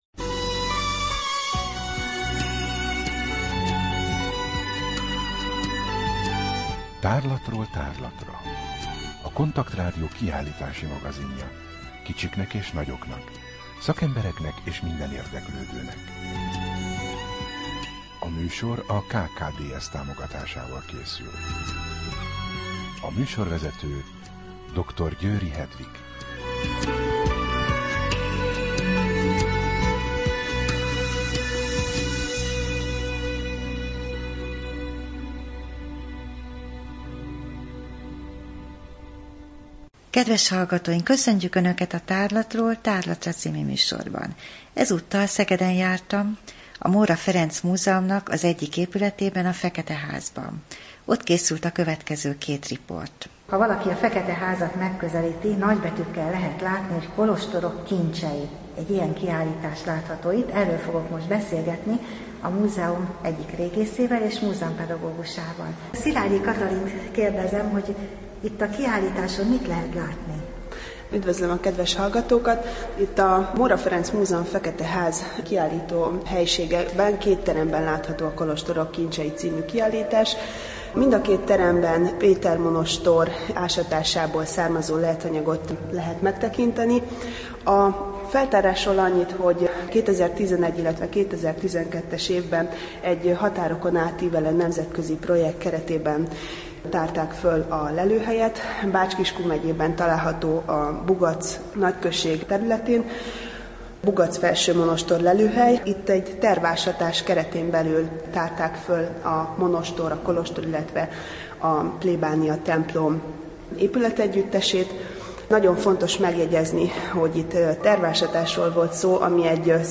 Rádió: Tárlatról tárlatra Adás dátuma: 2014, February 17 Tárlatról tárlatra / KONTAKT Rádió (87,6 MHz) 2014 február 17. A műsor felépítése: I. Kaleidoszkóp / kiállítási hírek II. Bemutatjuk / Kolostorok kincsei – Szeged, Fekete Ház A műsor vendége